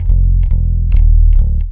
BASS 2 139-R.wav